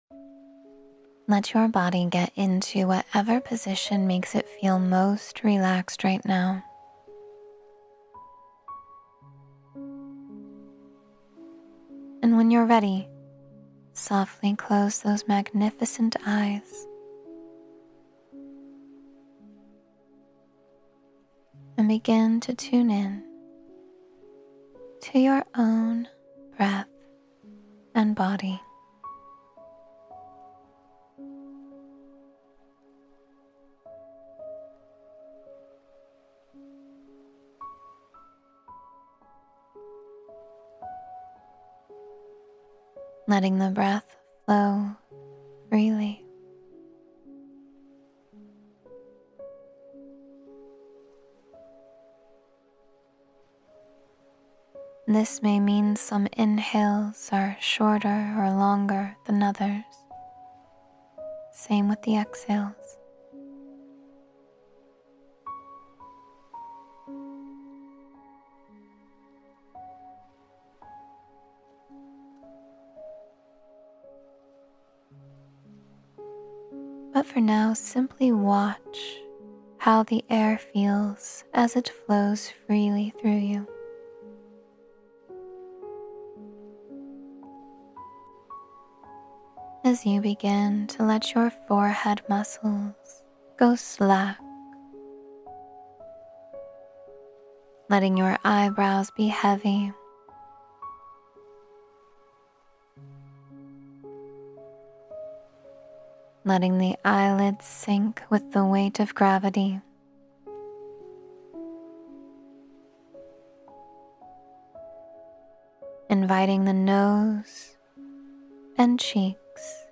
Relax Deeply with This Guided Session — Meditation for Total Relaxation